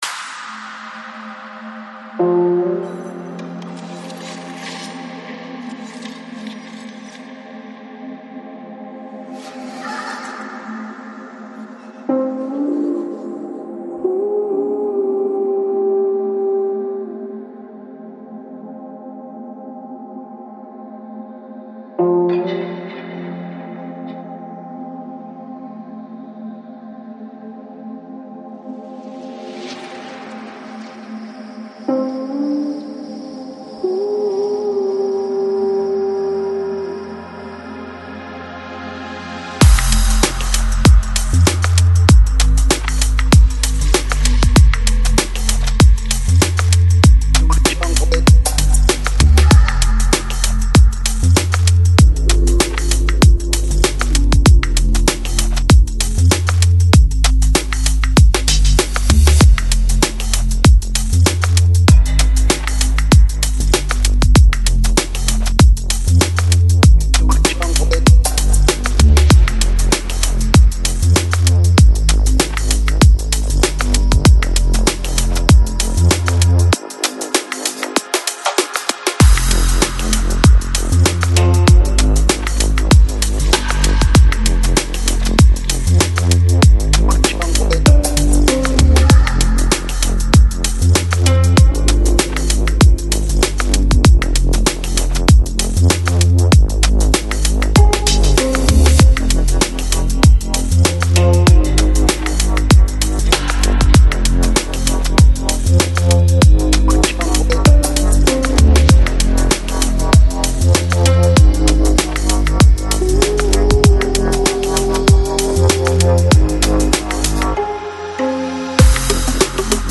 Жанр: Psychedelic, Dub